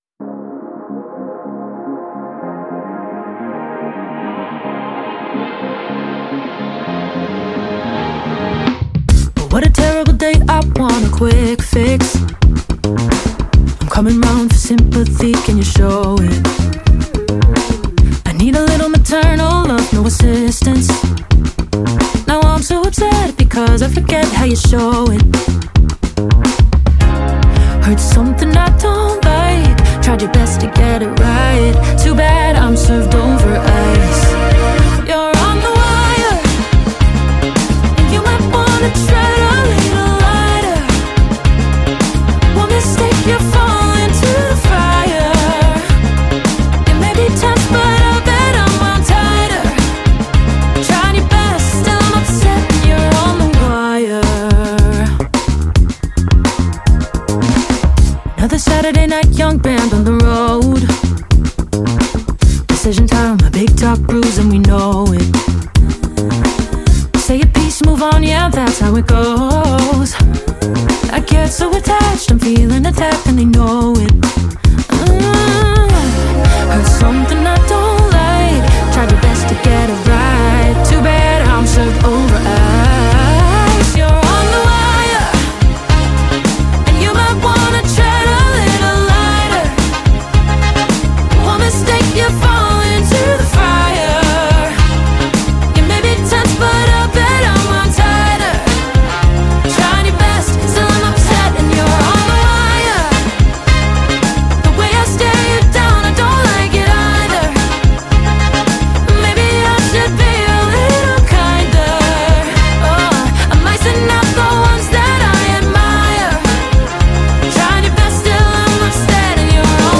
смесь соула, фанка, R& B и поп-музыки